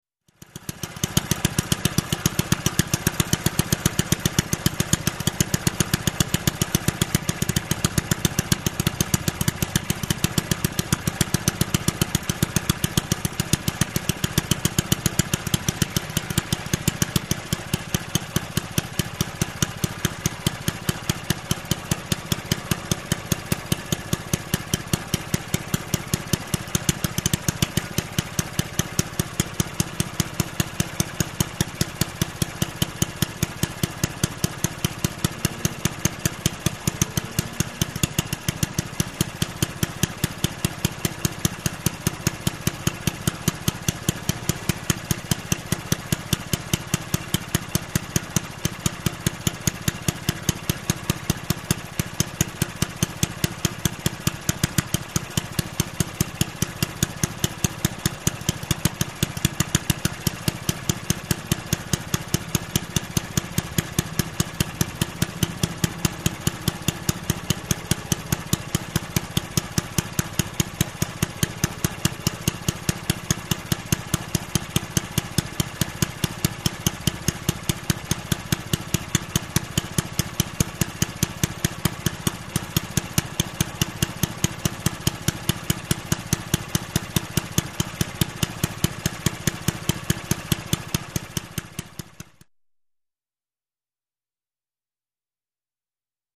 Lawnmower
Lawnmower Engine Steady, 9 Horse Power Wisconsin Motor, Slow Idle.